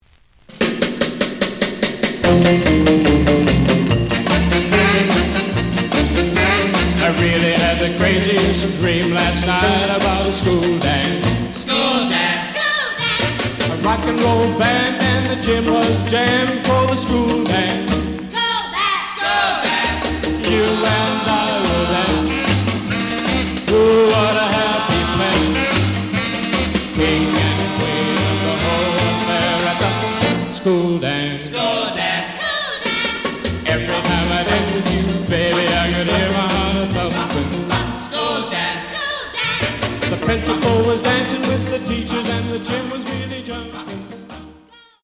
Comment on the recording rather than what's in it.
The audio sample is recorded from the actual item. Some damage on both side labels. Plays good.)